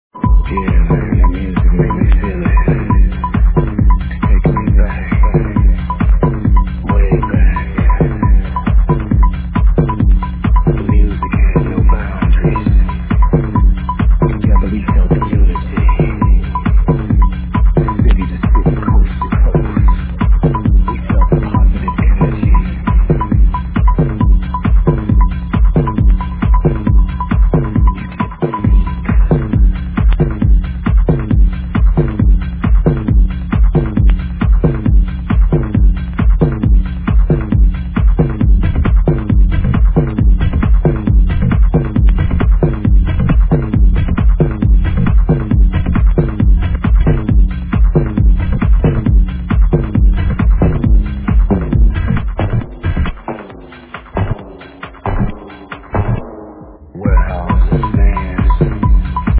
live
it's, obviously, not house. it's minimal